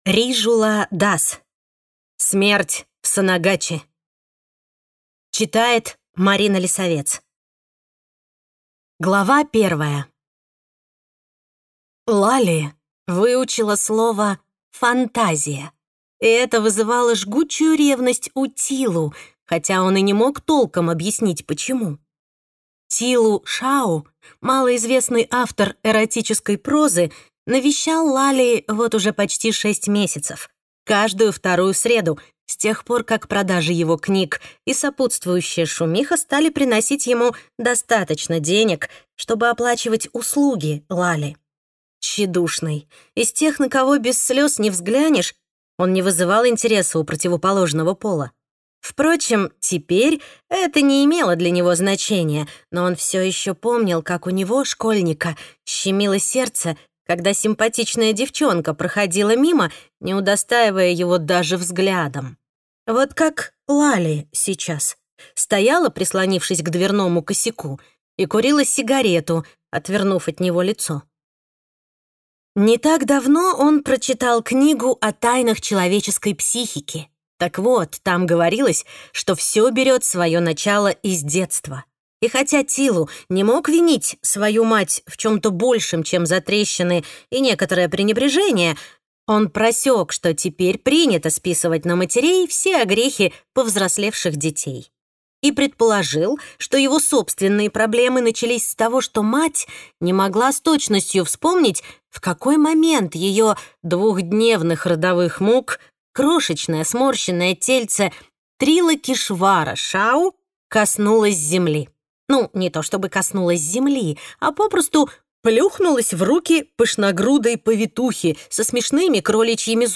Аудиокнига Смерть в Сонагачи | Библиотека аудиокниг